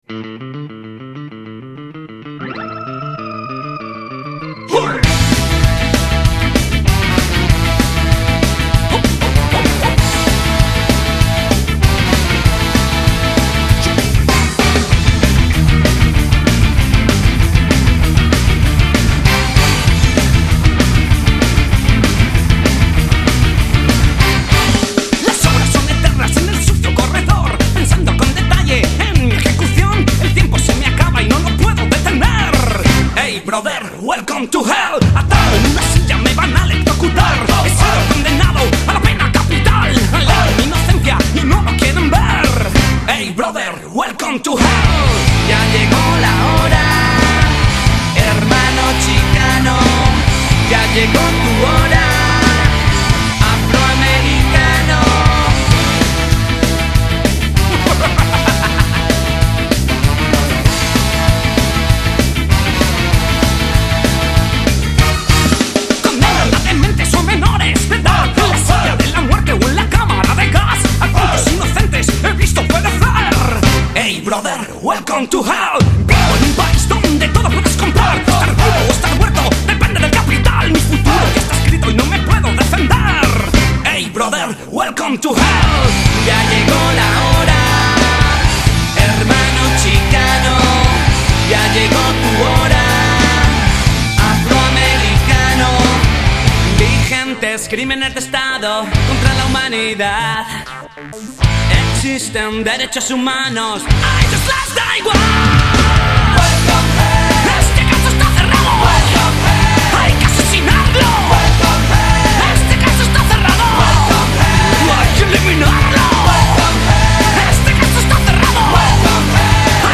La página con todo sobre este grupo de ska español